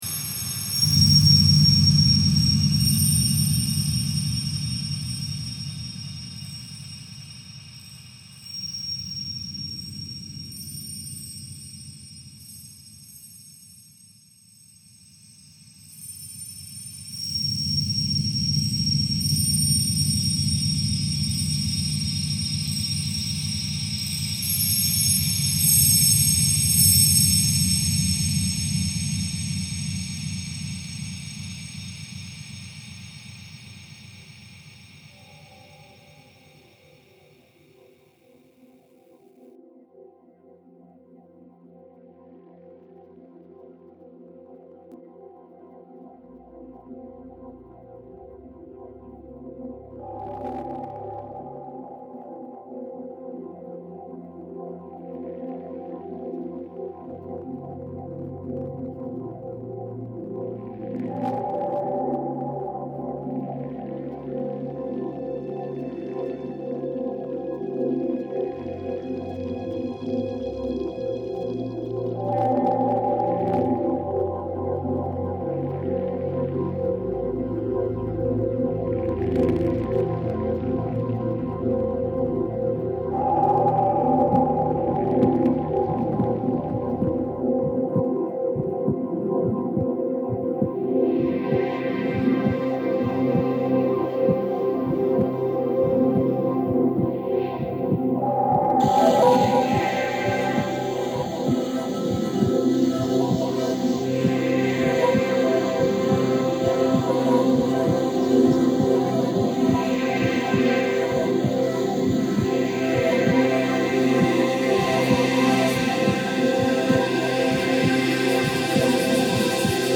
hill / 100 x 100 / oil, acrylics, enamel on canvas / 2014 The sound based on one of my early works with the windmill recordings